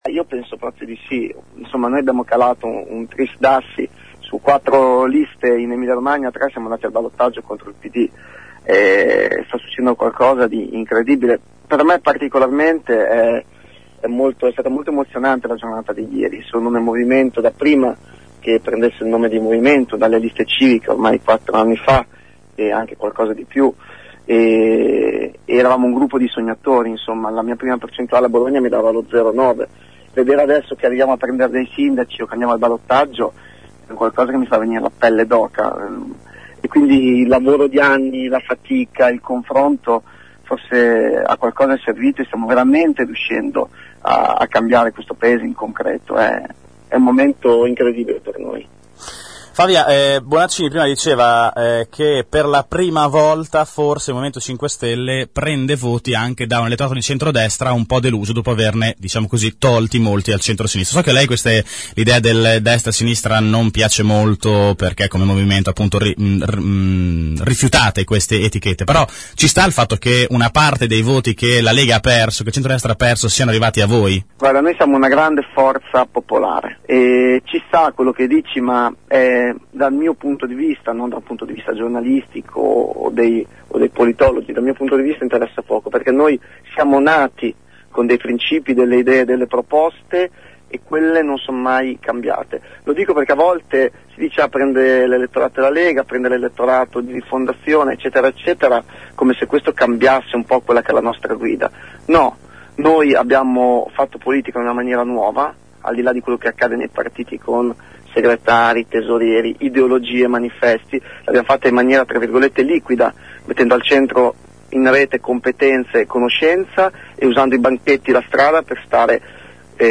ascolta Favia che risponde alla domanda “pensate di farcela”?